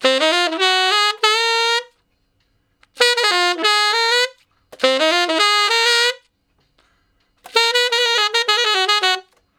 068 Ten Sax Straight (Ab) 03.wav